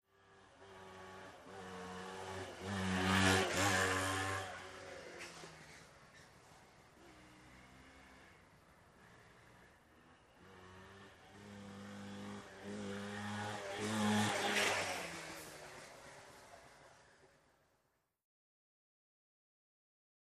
Motor Scooter, By Medium Speed, Cu, Skid, Return Fast Cu.